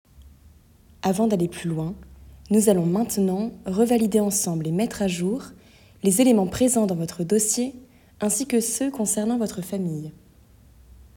Voix off Formation
- Mezzo-soprano